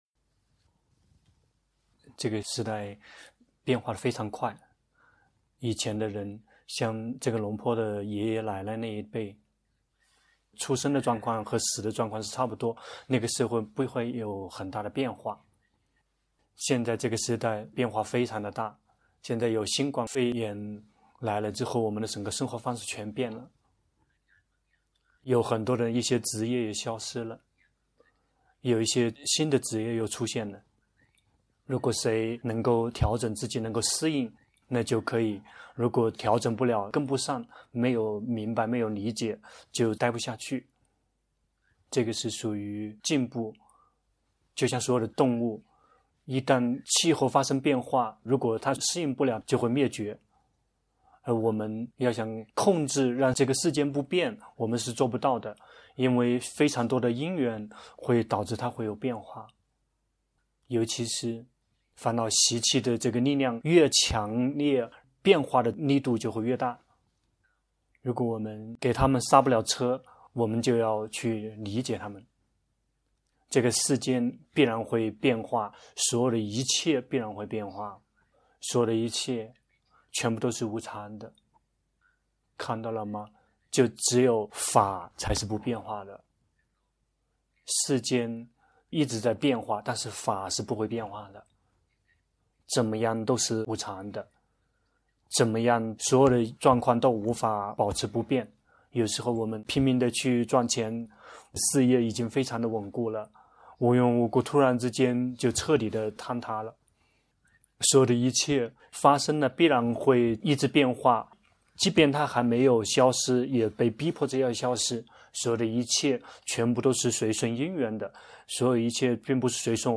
同聲翻譯